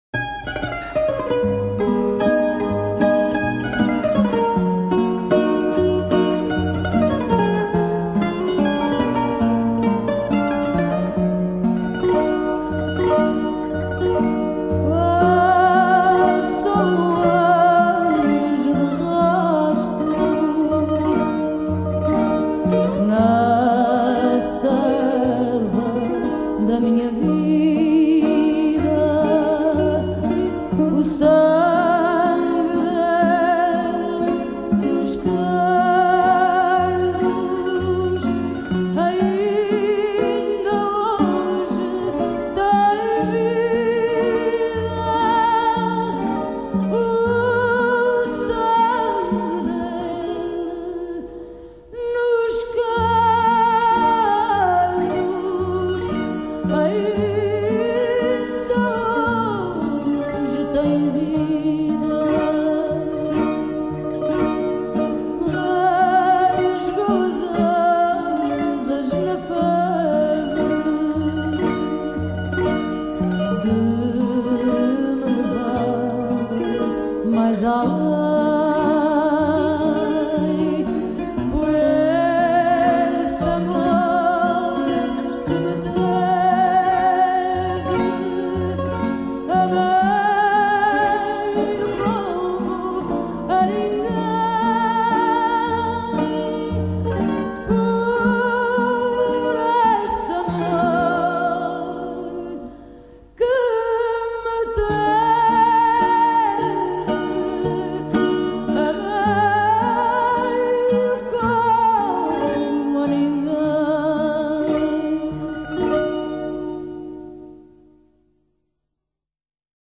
chant
guitare portugaise